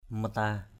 /mə-ta:/